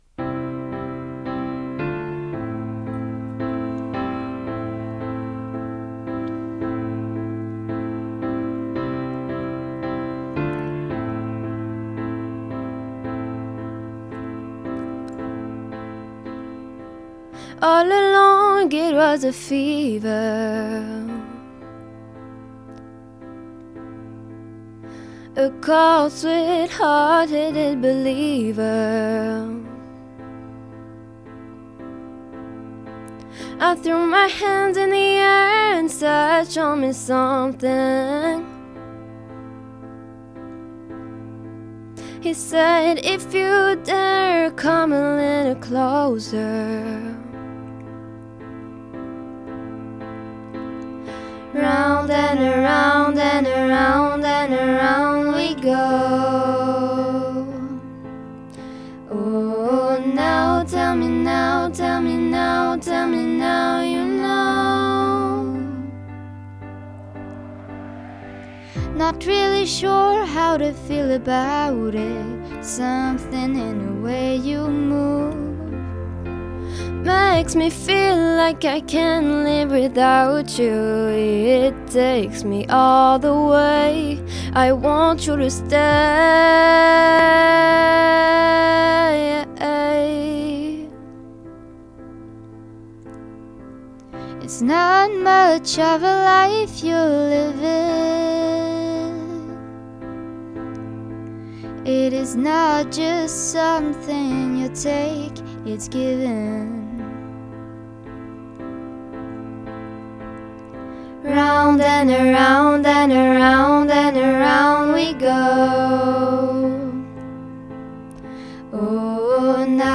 interprètent en duo